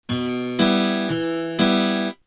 Способы аккомпанимента перебором
Em (2/4)